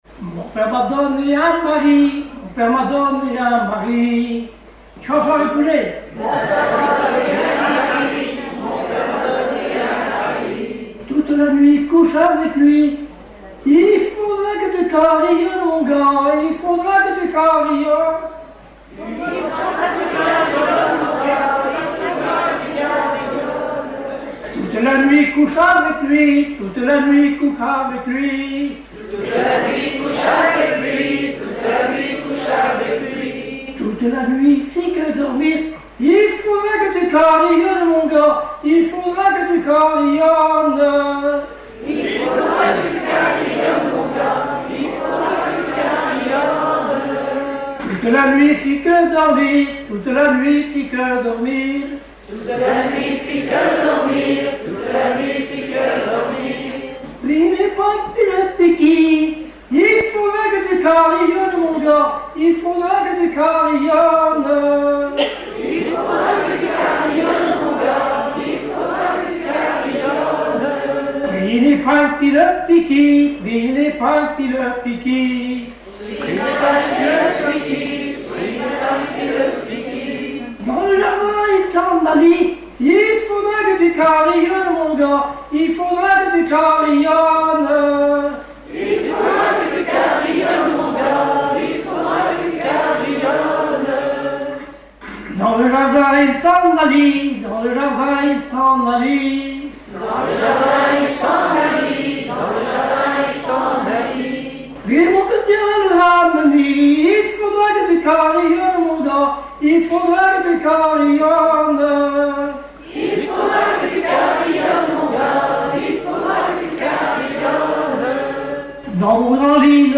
Genre laisse
Collectif, interviews Festival de la chanson pour Neptune F.M.
Catégorie Pièce musicale inédite